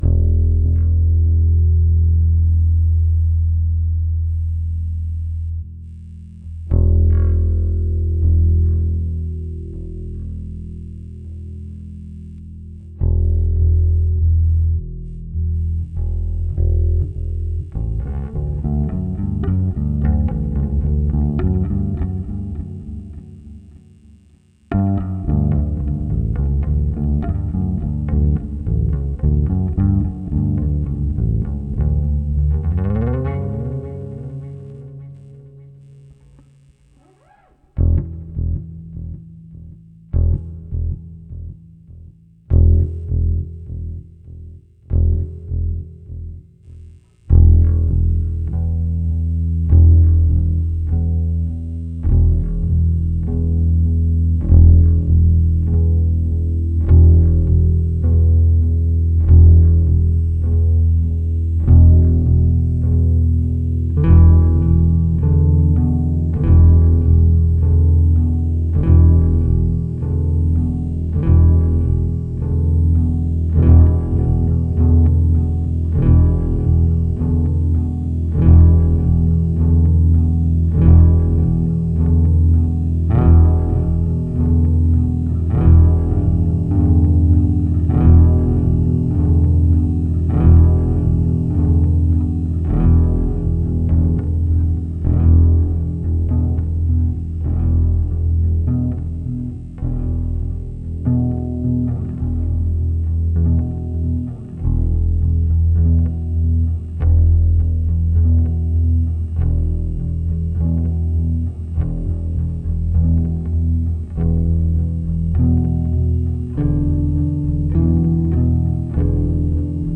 this felt good. it’s easy on the ears too. here’s a bass track from last night.